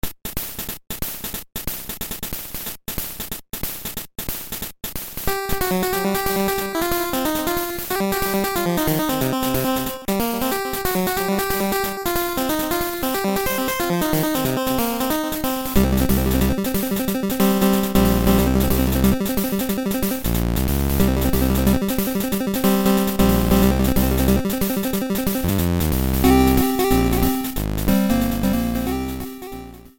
Mill theme
Fair use music sample